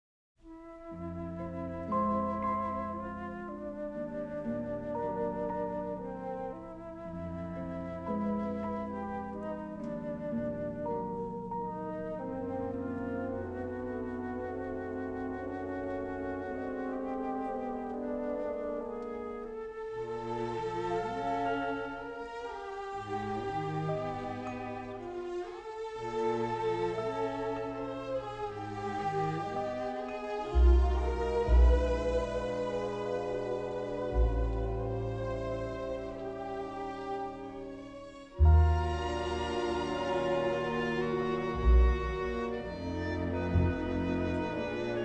each filled with exotic melody and instrumentation.
remixed in stereo from the original three-track masters.